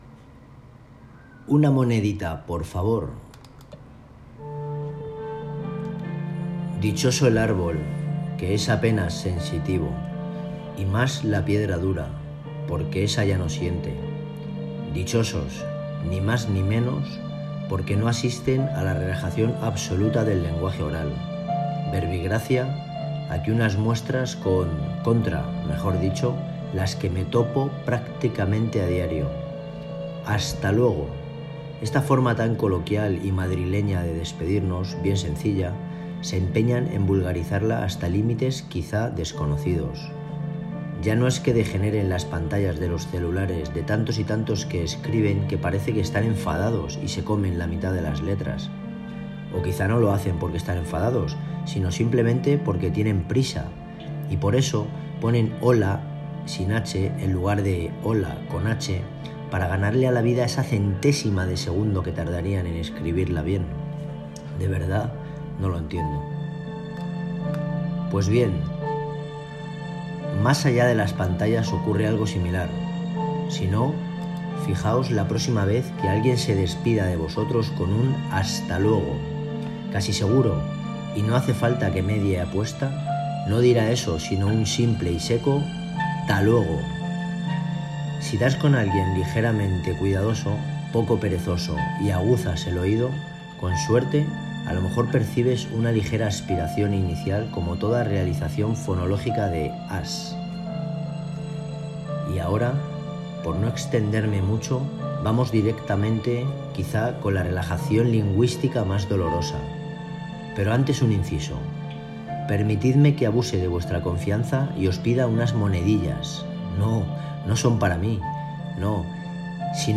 Una-monedita-por-favor-música-y-voz.m4a